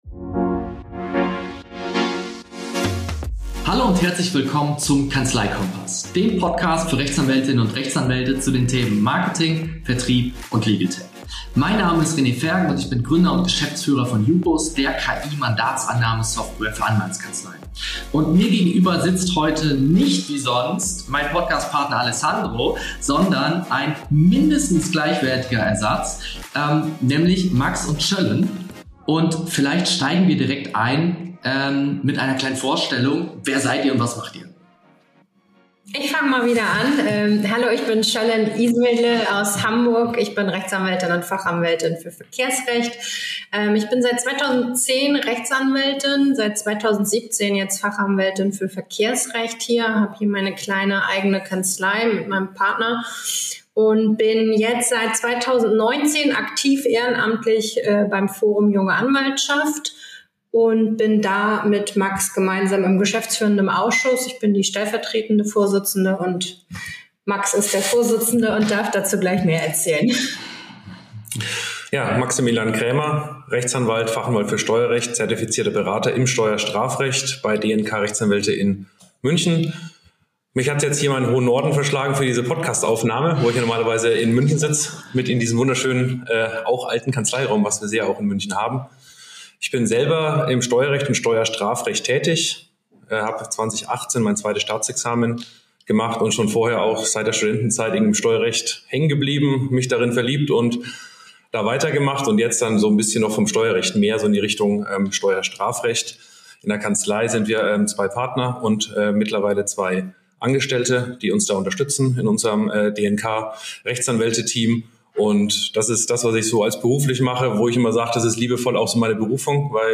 Willkommen zu einer weiteren Folge des Kanzleikompass-Podcasts.